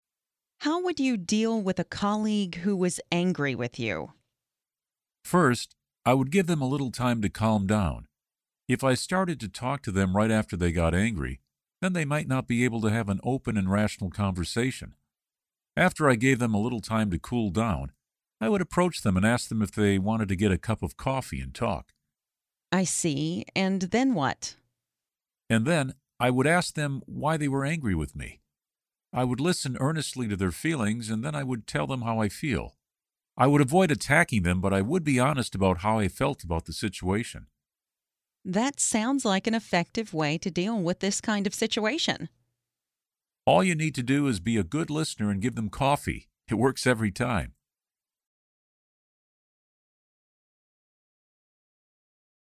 Learn different ways to answer the interview question 'How would you deal with a colleague who was angry with you?', listen to an example conversation, and study example sentences.